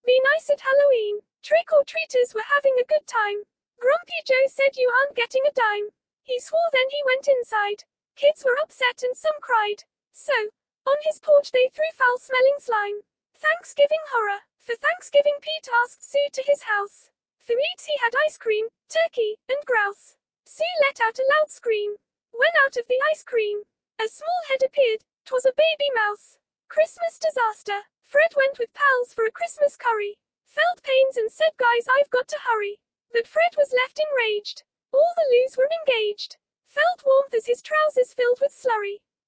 I tried text to speech and speeded it up, don't think anyone noticed lol